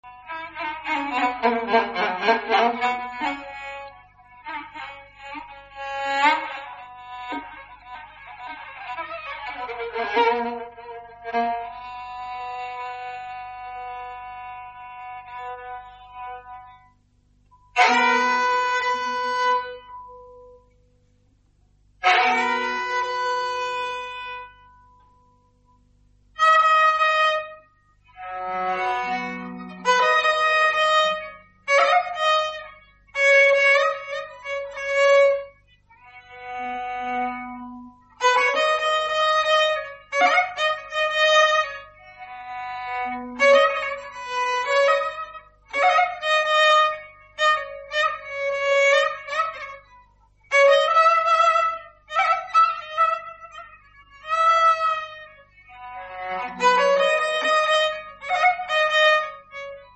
Çəqanə
Çəqanə - kamanla çalınan dörd simli çalğı alətidir.
İfaçı şişi yerə dayayaraq, aləti şaquli vəziyyətdə saxlayır və sağ əlində tutduğu kamanla səsləndirilir.